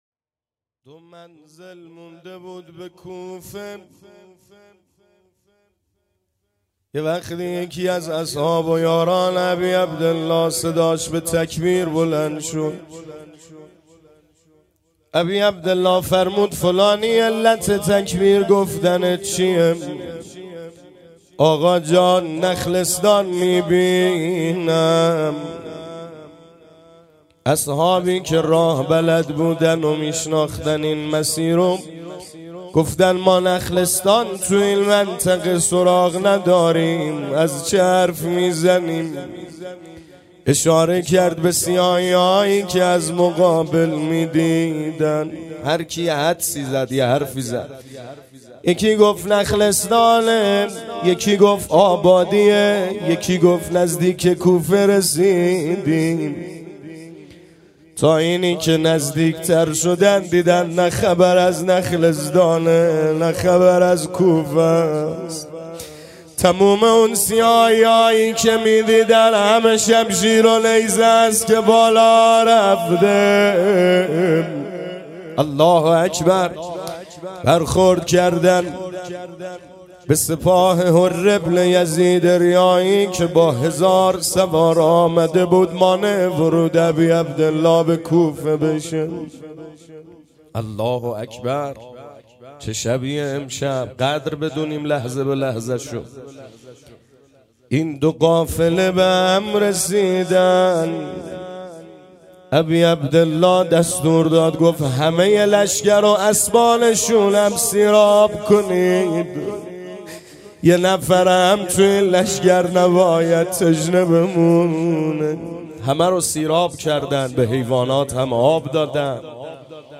گزارش صوتی شب چهارم محرم 98 | هیأت محبان حضرت زهرا سلام الله علیها زاهدان